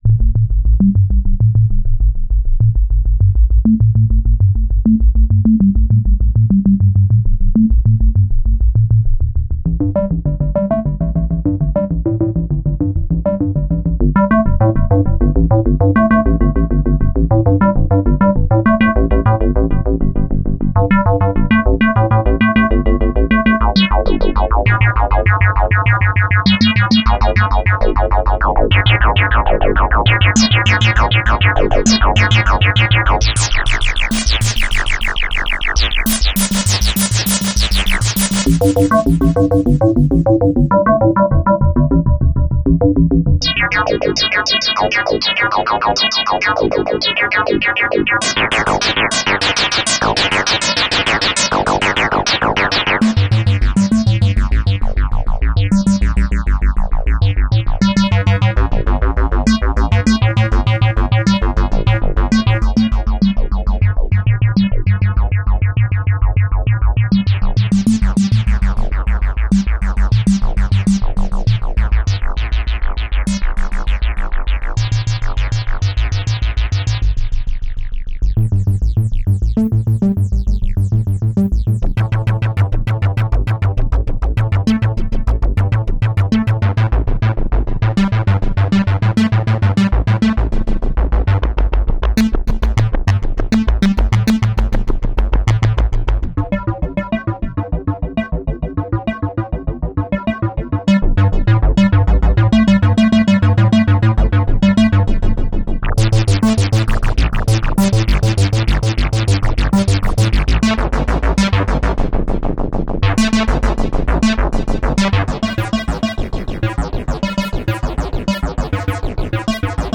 Korg 01/W ... W = Waveshaping
:play Korg 01/W Sinus wave thru the 59 waveshapers:
korg_01_w_-_sounds_demo_-_59_waveshaper.mp3